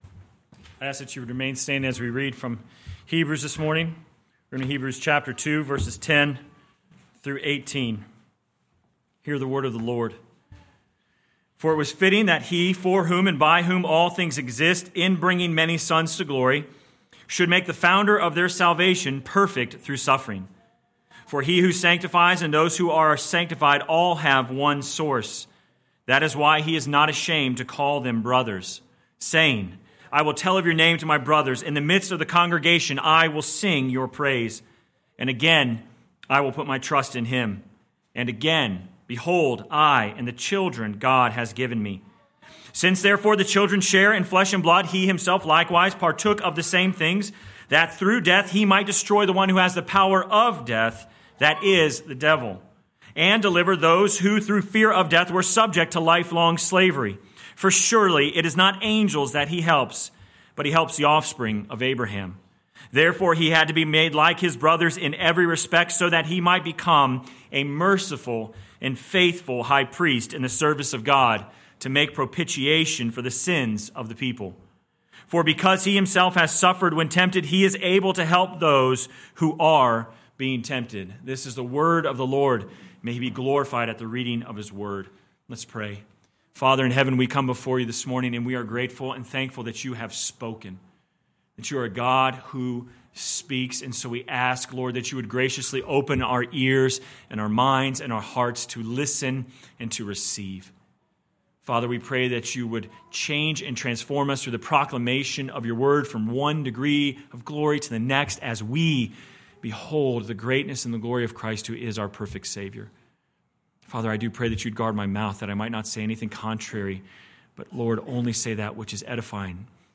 Sermon Text: Hebrews 2:10-18 First Reading: Psalm 23 Second Reading: 2 Corinthians 1:1-7